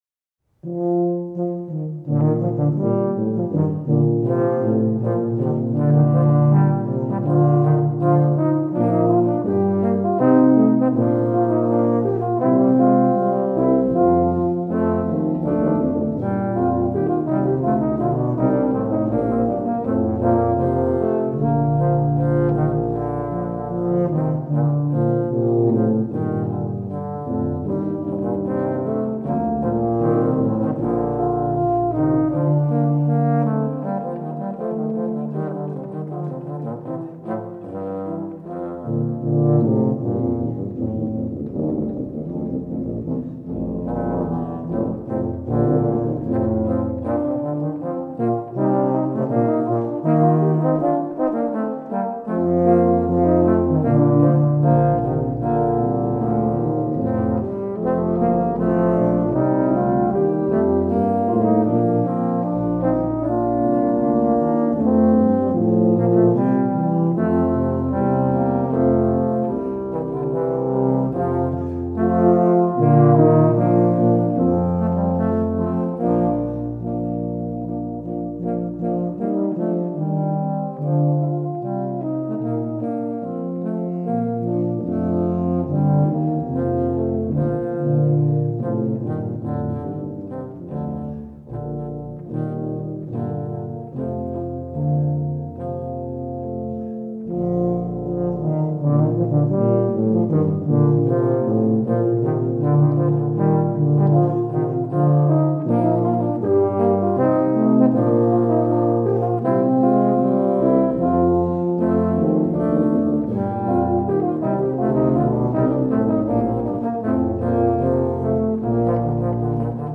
Voicing: Tuba/Euph